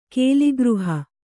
♪ kēli gřha